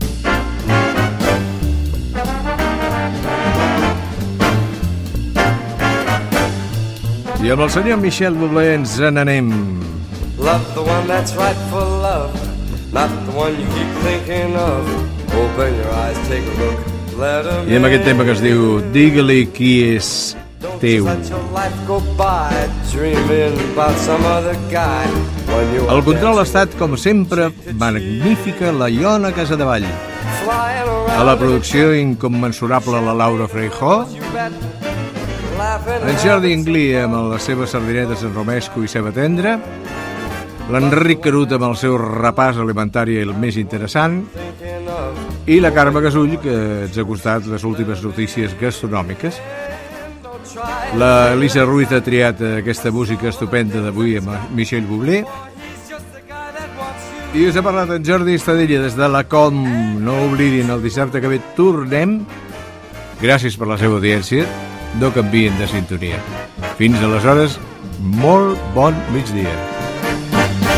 Presentador/a
FM